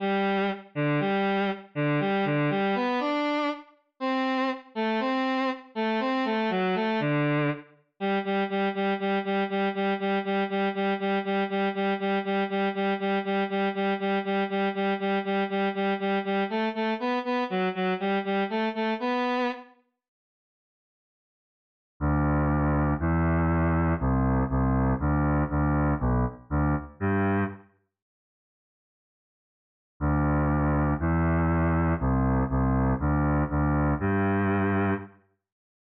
eine-kleine-bass.wav